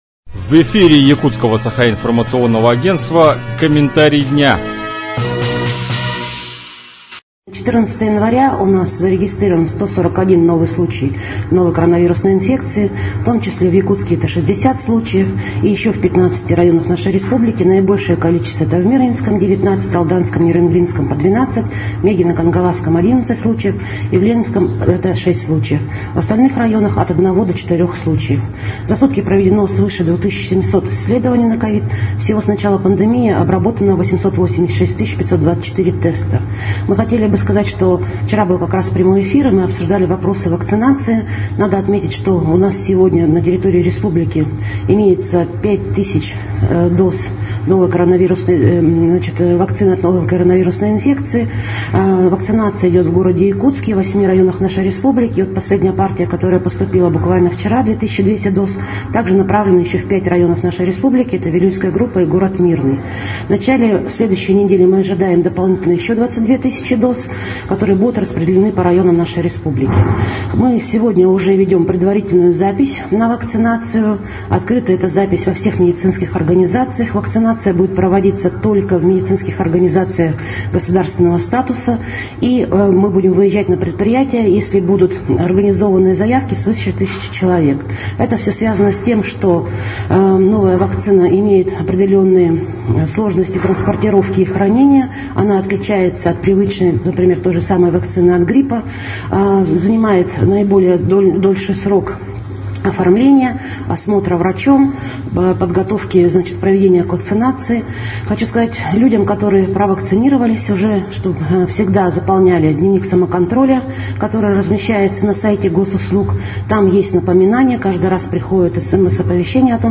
Об обстановке в Якутии на 14 января рассказала министр здравоохранения Якутии Елена Борисова.